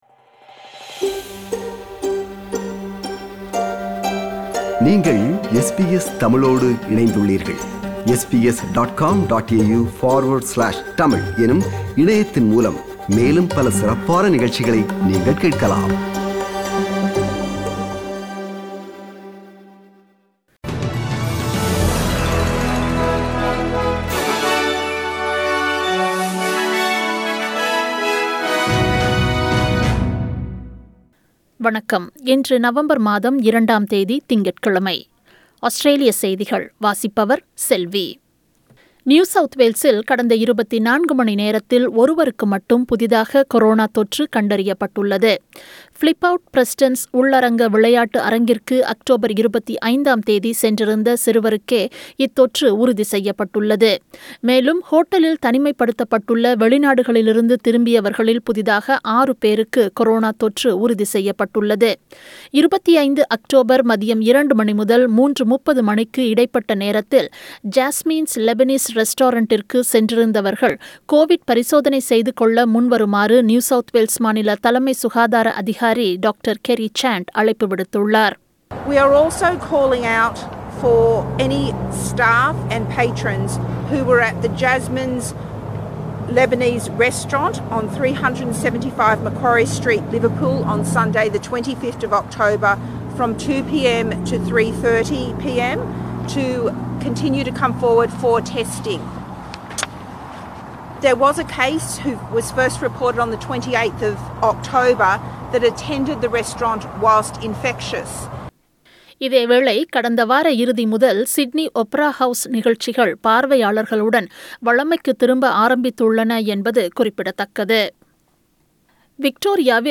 Australian news bulletin for Monday 02 November 2020.